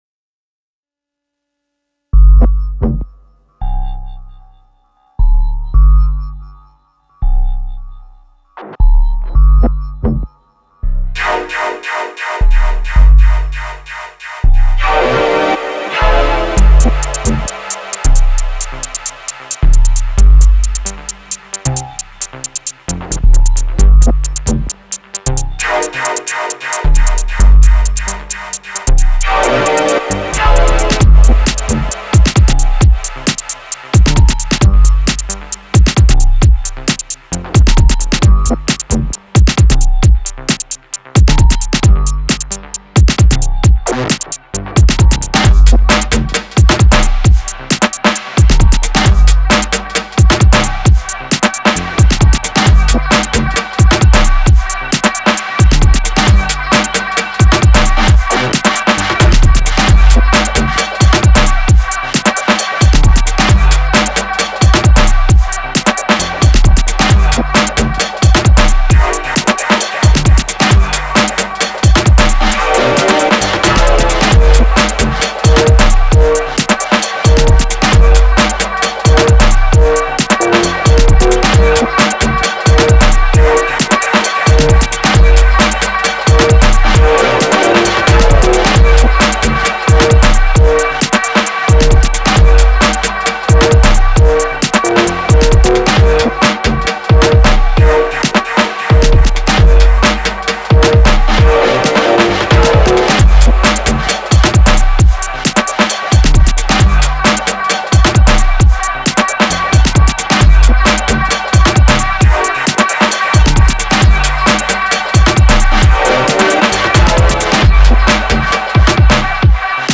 my morning jam as well.